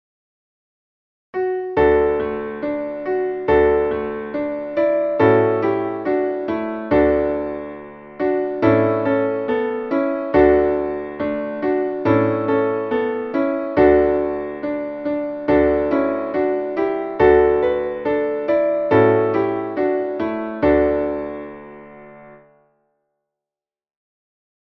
Traditionelles Winterlied